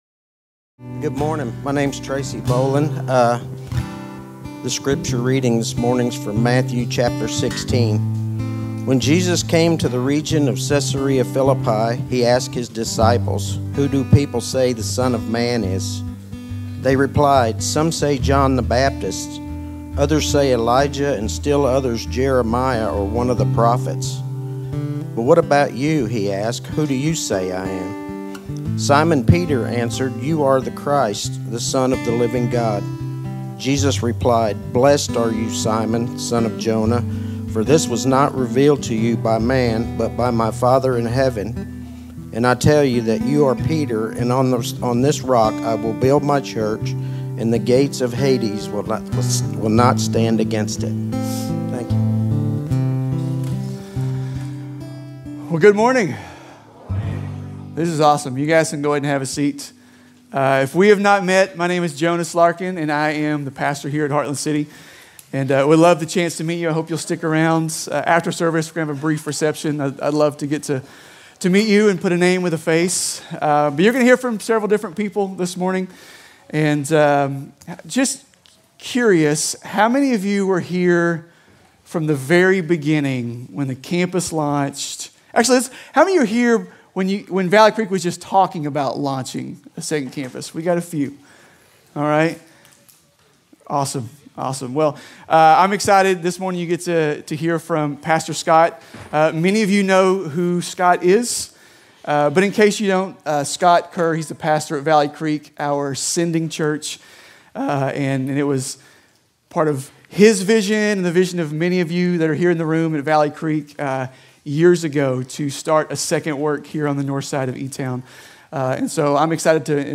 Commissioning Service